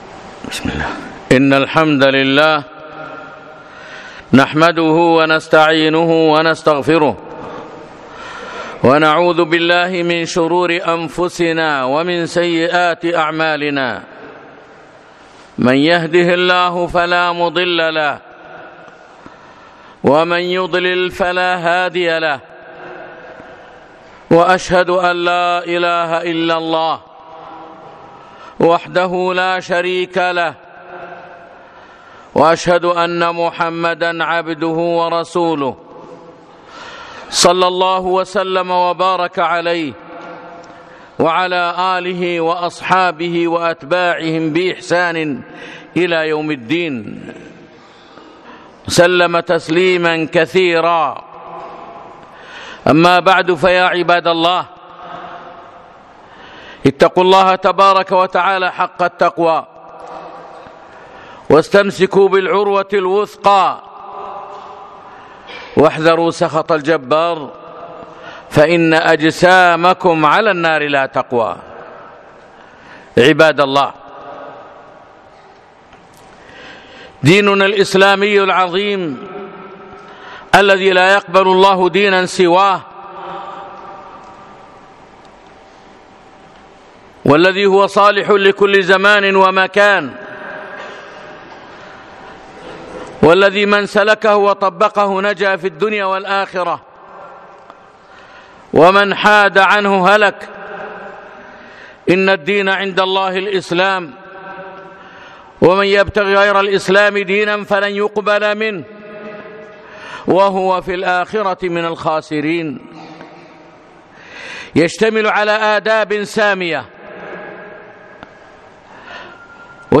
يوم الجمعة 9 3 2018 مسجد الحمدان الفنطاس
اعط الطريق - خطبة